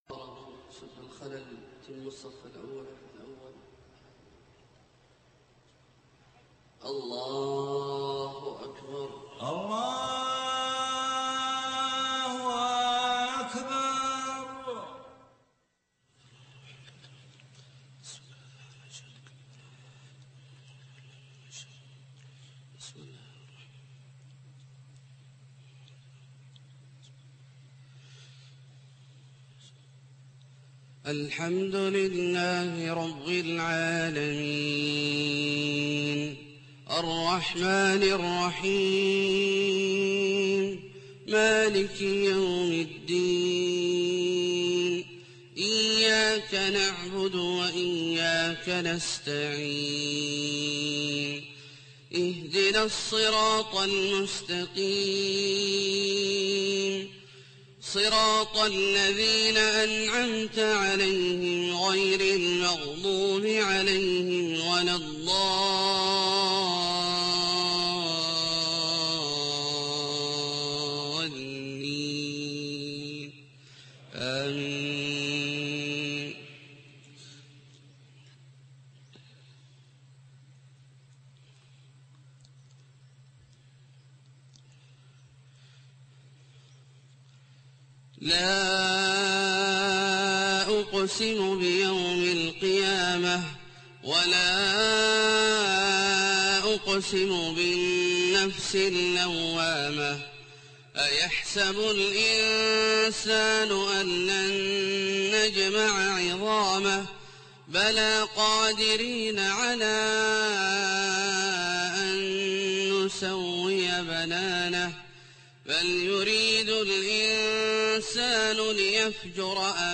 فجر 6-7-1429 سورتي القيامة و التكوير > ١٤٢٩ هـ > الفروض - تلاوات عبدالله الجهني